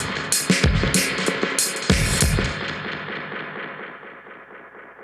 Index of /musicradar/dub-designer-samples/95bpm/Beats
DD_BeatFXA_95-02.wav